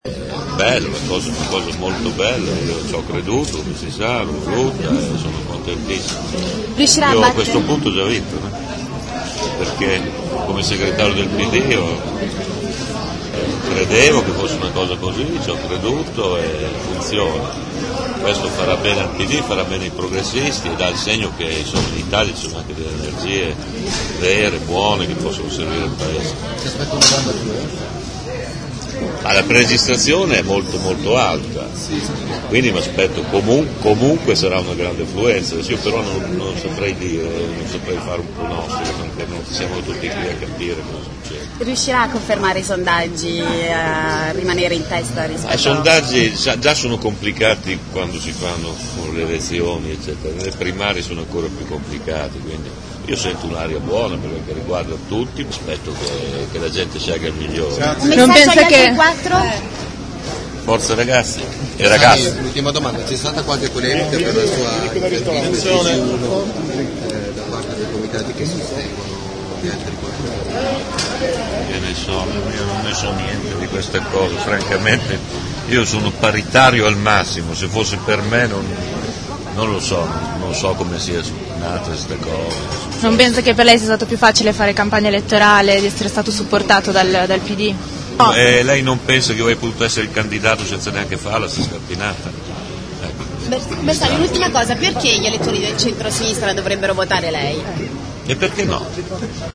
23 nov.- Una pre chiusura di campagna per le primarie con un Paladozza non stipato, c’erano circa 2500 persone, per il segretario del Pd Pierluigi Bersani.
Bersani ha scaldato il pubblico, non proprio giovanissimo del Paladozza, con un discorso di quasi un’ora. Un discorso incentrato fortemente sulla necessità di agganciare le persone disilluse dalla politica.